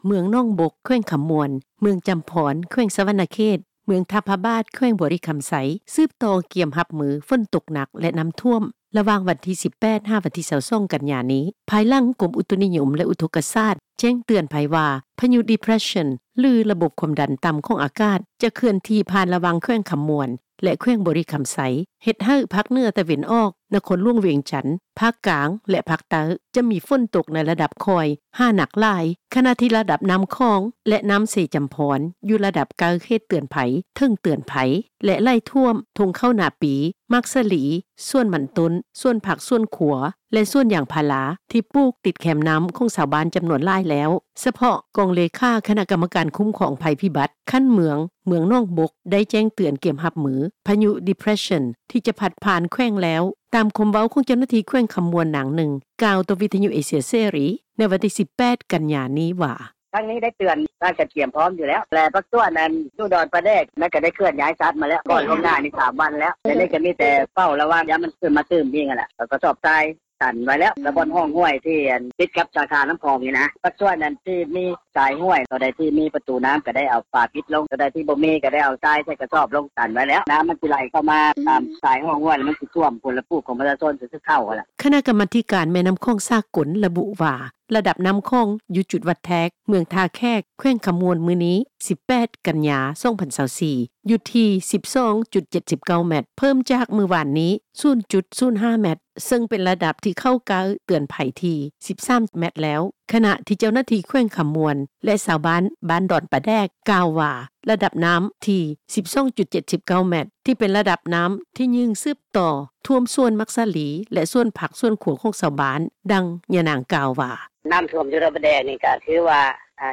ສະເພາະ ກອງເລຂາ ຄະນະກໍາມະການຄຸ້ມຄອງ ໄພພິບັດ ຂັ້ນເມືອງ ເມືອງໜອງບົກ ໄດ້ແຈ້ງເຕືອນ ກຽມຮັບມື ພາຍຸ ດີເປ່ຣຊັນ ທີ່ຈະພັດຜ່ານແຂວງແລ້ວ. ຕາມຄວາມເວົ້າຂອງ ເຈົ້າໜ້າທີ່ແຂວງຄໍາມ່ວນ ນາງນຶ່ງ ກ່າວຕໍ່ວິທຍຸ ເອເຊັຽເສຣີ ໃນວັນທີ 18 ກັນຍານີ້ວ່າ: